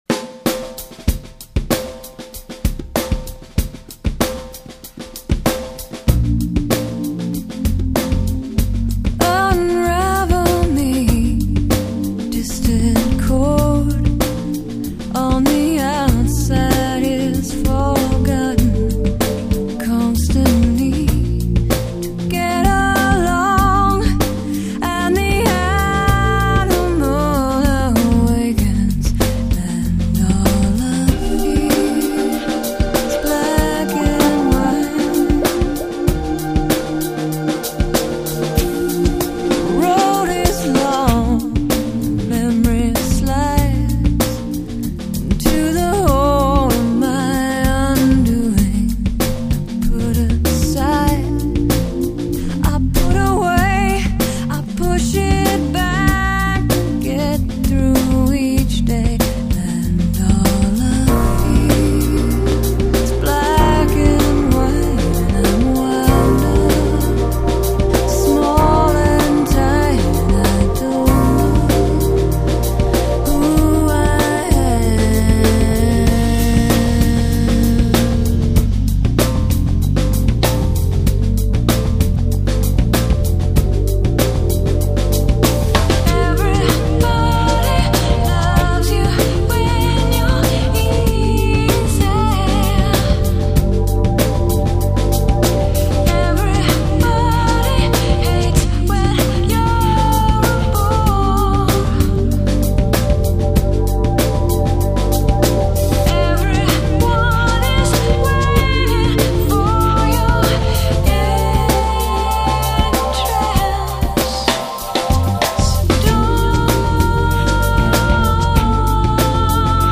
天籁美声
她的唱腔中带着标志性的鼻音，轻柔地，舒缓地衬托出一种难以言传的味道